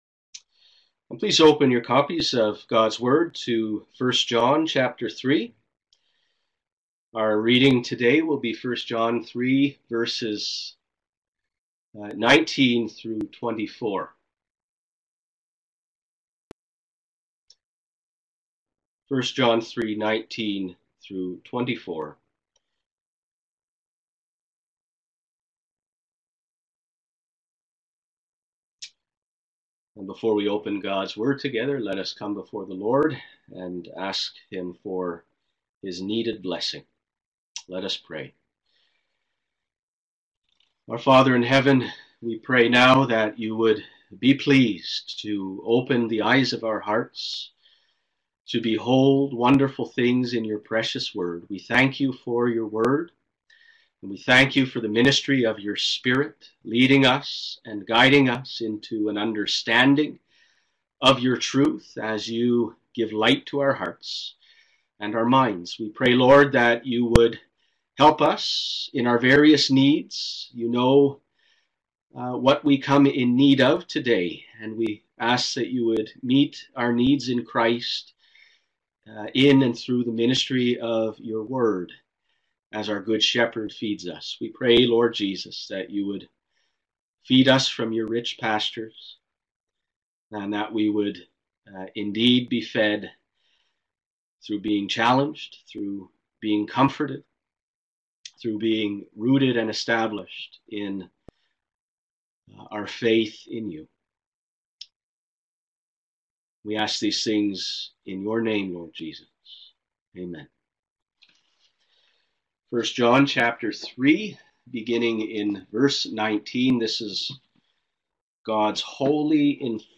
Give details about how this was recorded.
A Heart at Rest in God’s Presence (1 John 3:19-24) *Live-streamed Recording*